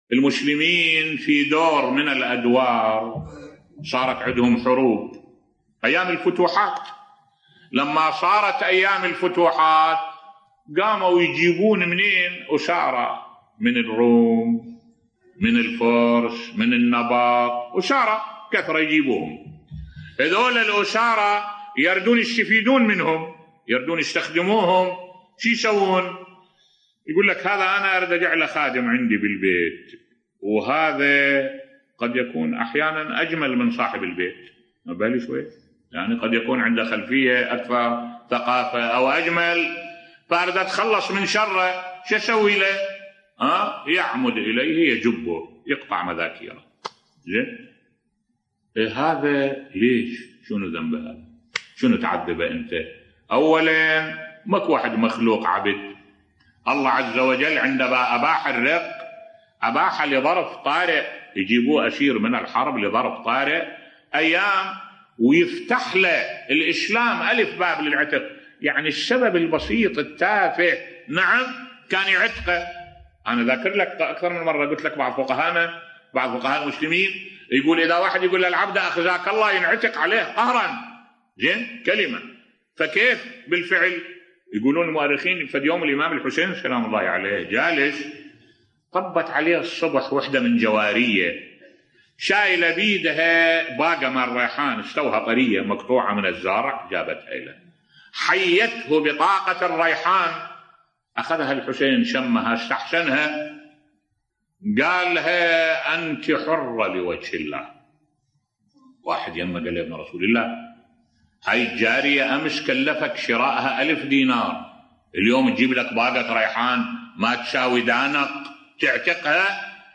ملف صوتی الله عزوجل أباح الرِق لظرف طارئ بصوت الشيخ الدكتور أحمد الوائلي